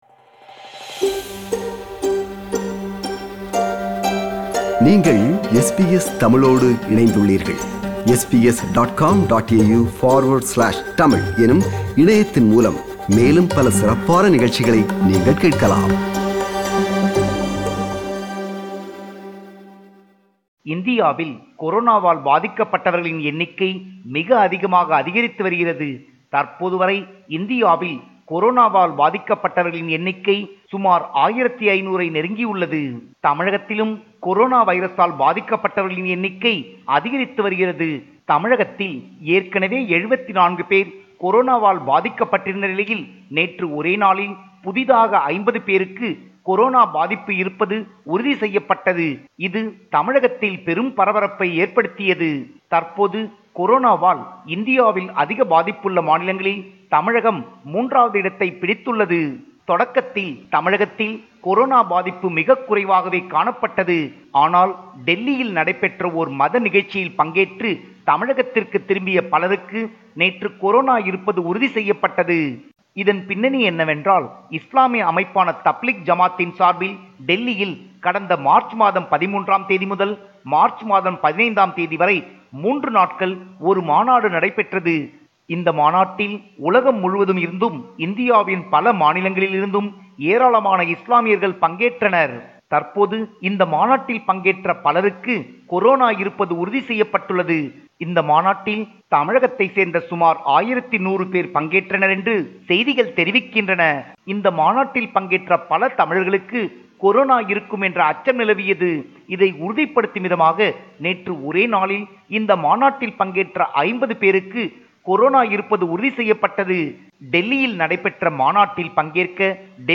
compiled a report focusing on major events/news in Tamil Nadu / India.